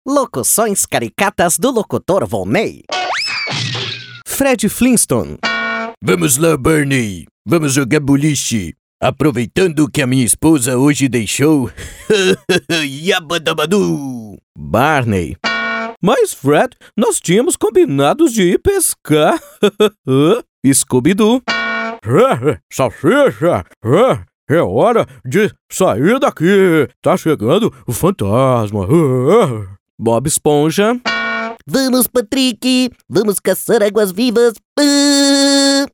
Locuções Caricatas:
Impacto
Animada